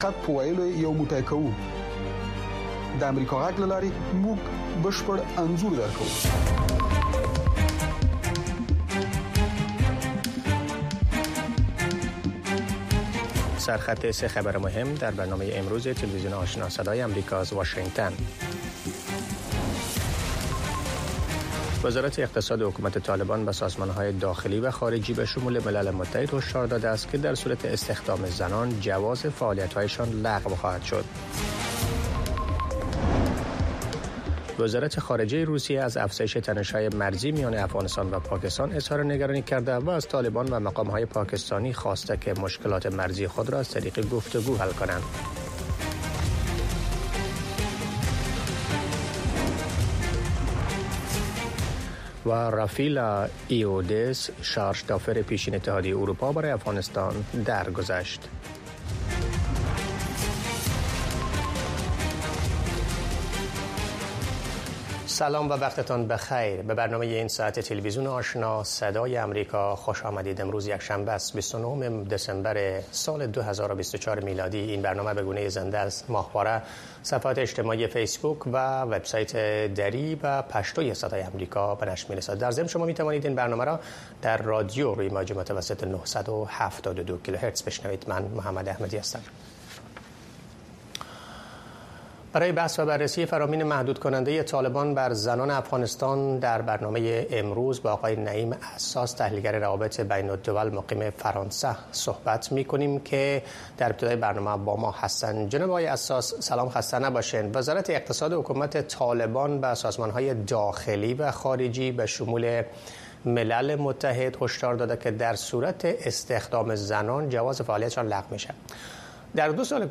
تازه‌ترین خبرهای افغانستان، منطقه و جهان، گزارش‌های جالب و معلوماتی از سراسر جهان، مصاحبه‌های مسوولان و صاحب‌نظران، صدای شما و سایر مطالب را در برنامهٔ خبری آشنا از روزهای شنبه تا پنج‌شنبه در رادیو، ماهواره و شبکه های دیجیتلی صدای امریکا دنبال کنید.